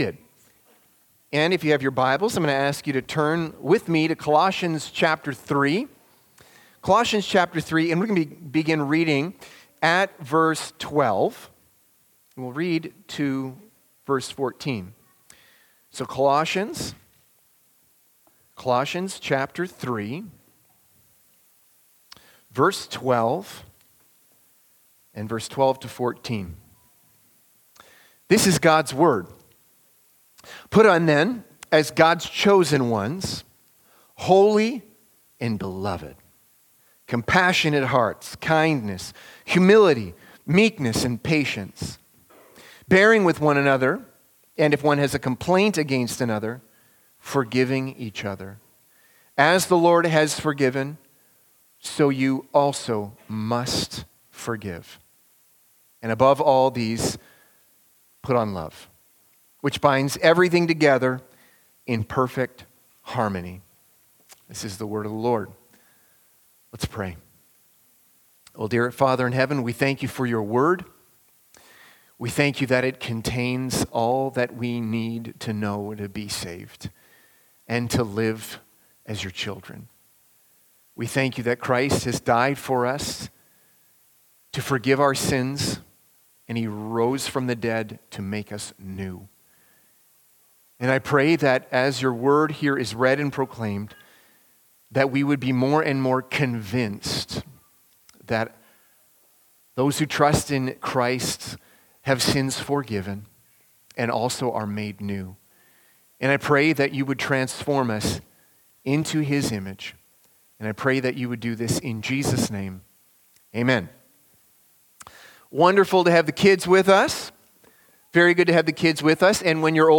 Sermons | Park City Gospel Church